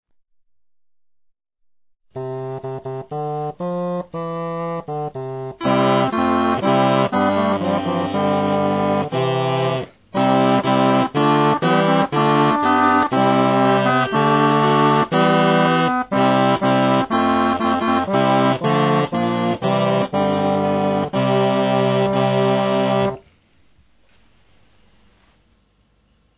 The picture shows a set of cornamusen (which are essentially straight crumhorns) consisting of a bass, two tenors, an alto and a soprano.
They are multitracked recordings the first two arranged for two sopranos, an alto and a bass, the third for an alto two tenors and a bass.